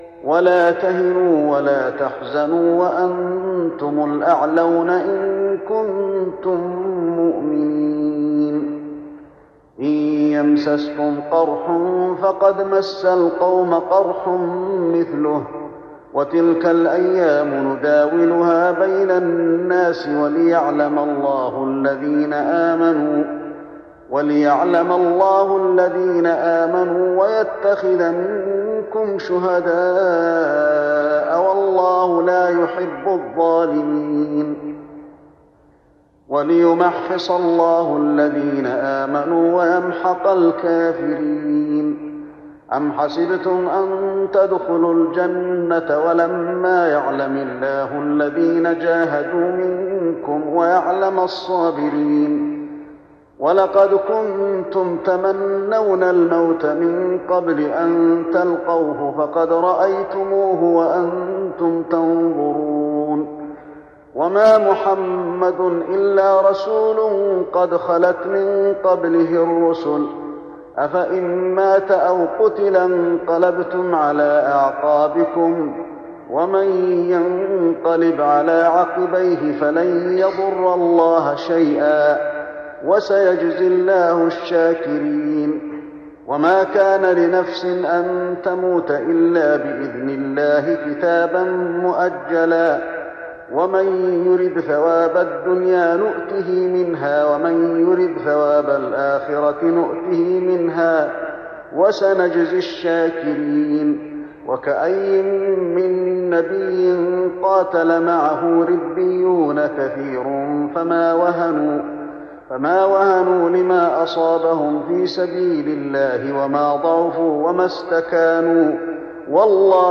تراويح رمضان 1415هـ من سورة آل عمران (139-200) Taraweeh Ramadan 1415H from Surah Aal-i-Imraan > تراويح الحرم النبوي عام 1415 🕌 > التراويح - تلاوات الحرمين